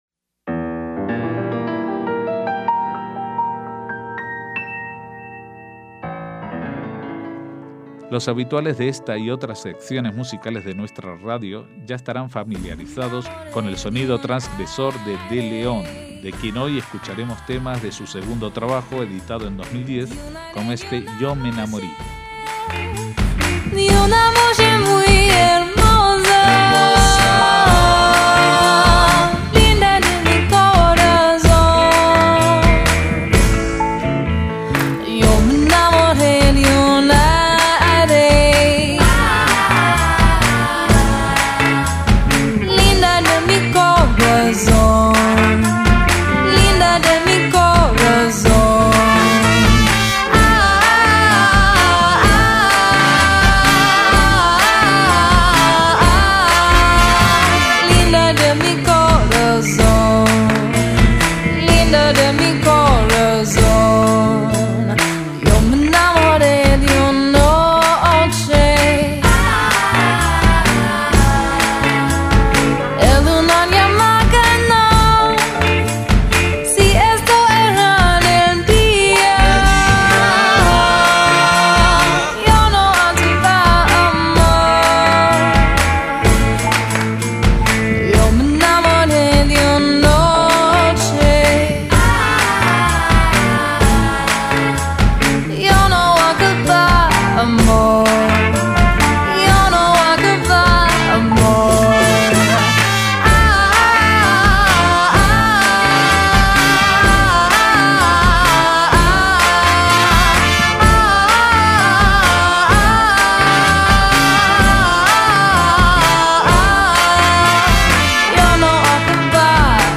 MÚSICA SEFARDÍ
rock indie
guitarra y banjo
teclado, armónica, glockenspiel
trompeta, sintetizador